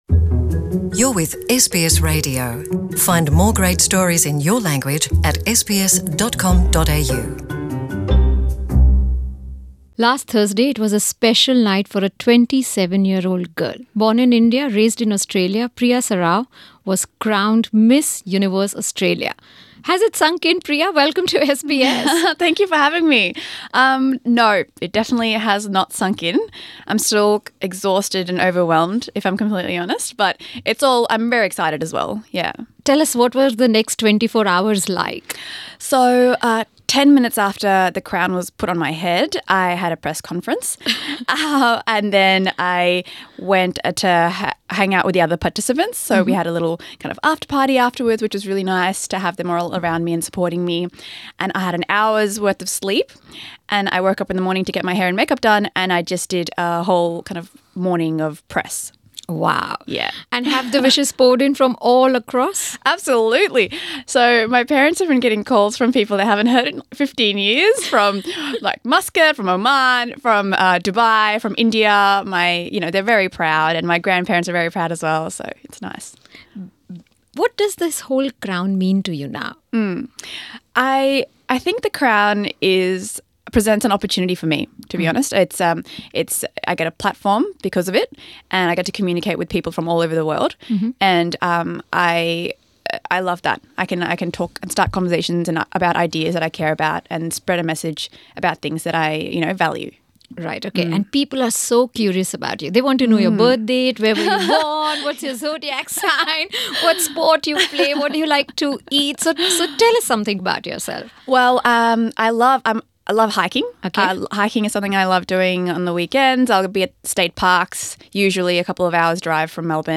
In a special interview with SBS Hindi, Priya talks about her migrant journey, her Indian roots and her plans for the Miss Universe contest.